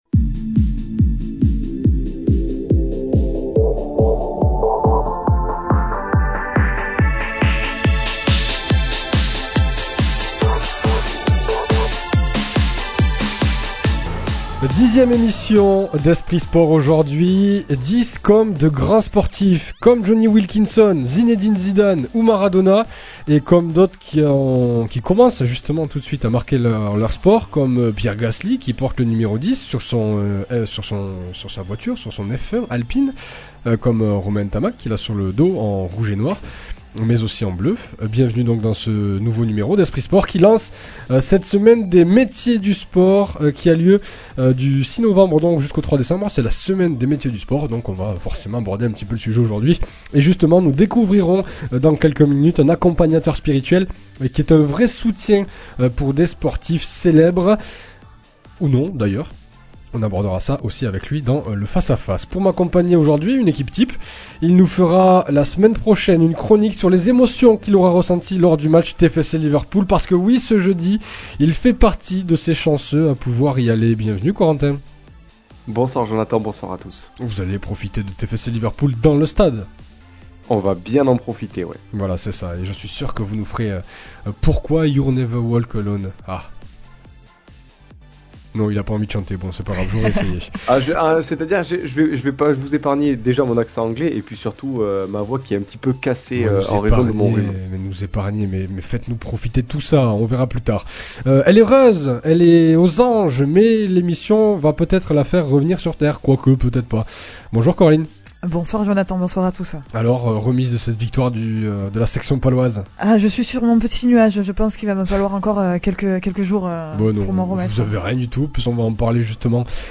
Même en effectif réduit Esprit Sport vous retrouve pour une heure de sport avec au programme :